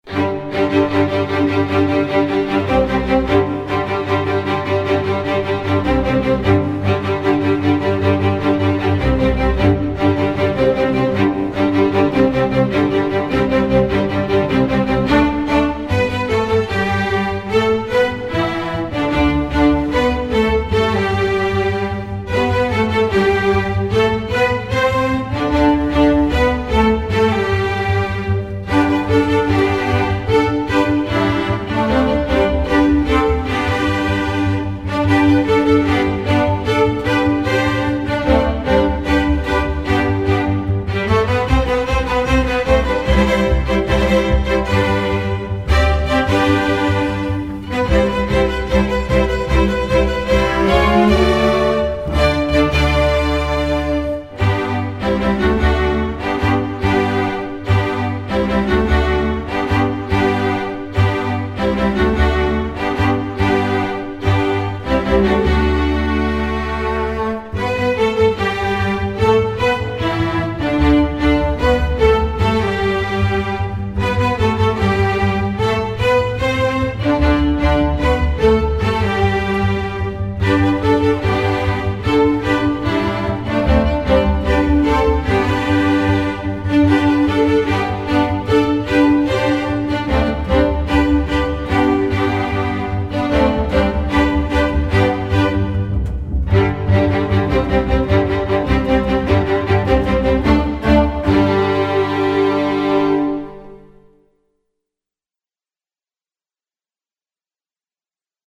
instructional, children, film/tv, movies